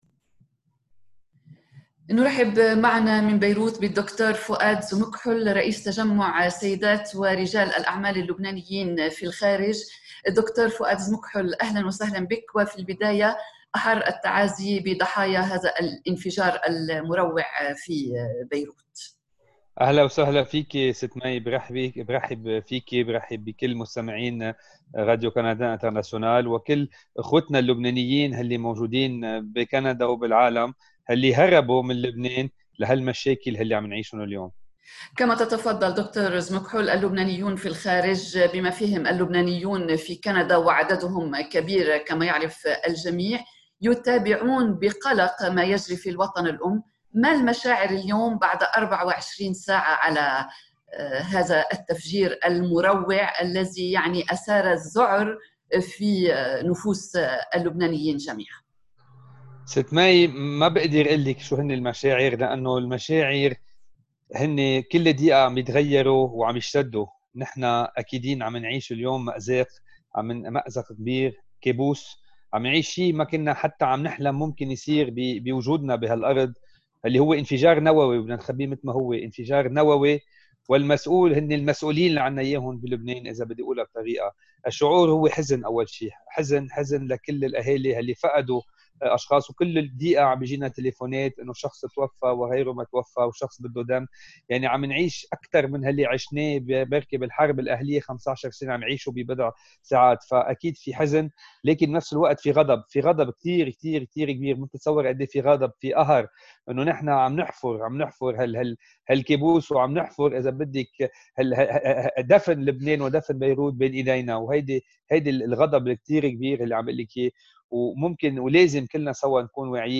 ويقول في مستهلّ حديثي معه إنّ مشاعر الحزن والغضب تسود البلاد، ويعيش اللبنانيّون في بضع ساعات ما عاشوه طوال 15 سنة من الحرب.